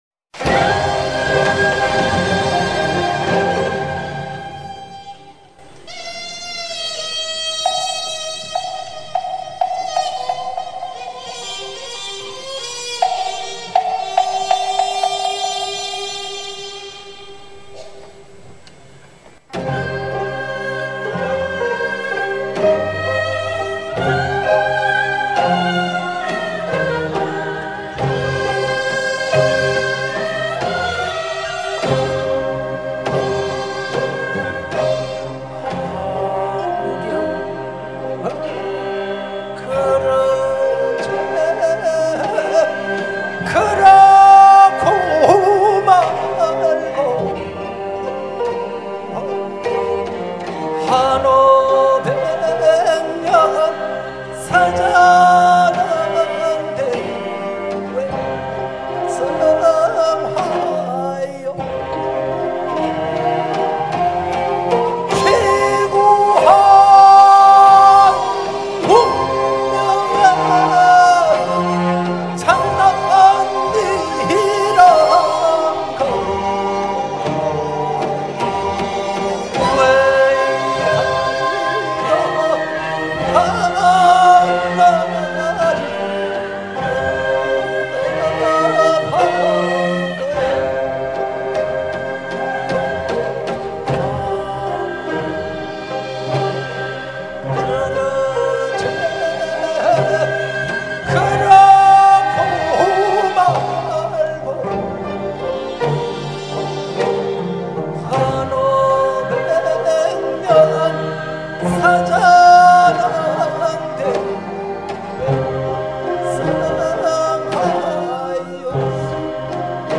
을숙도문화회관 대극장